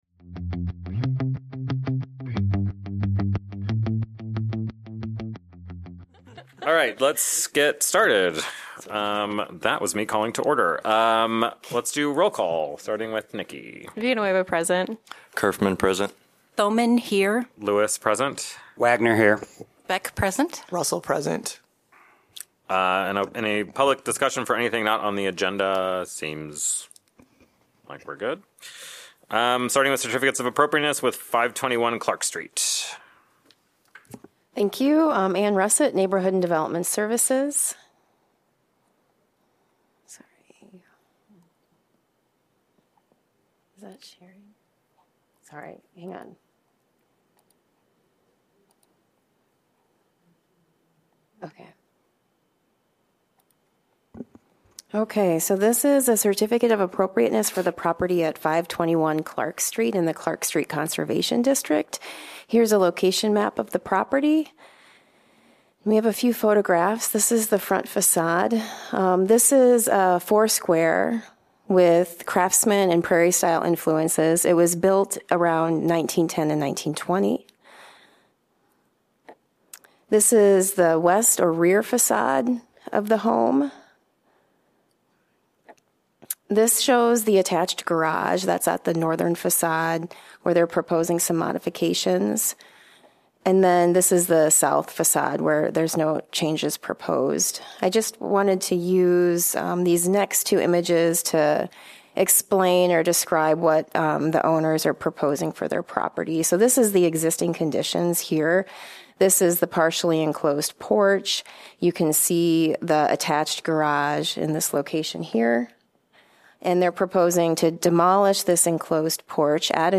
Regular meeting of the Iowa City Historic Preservation Commission.